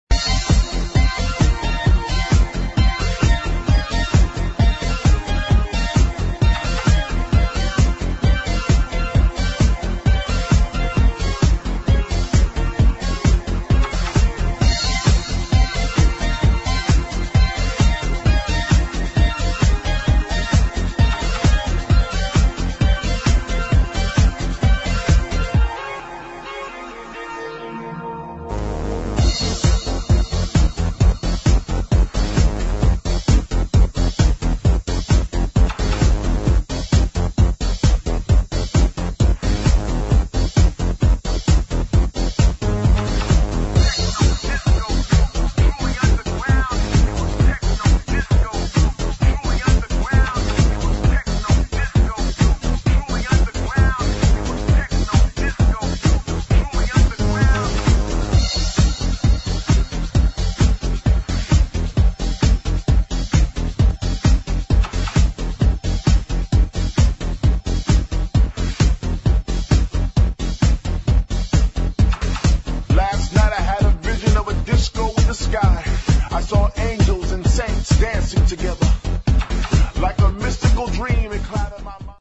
[ BREAKS ]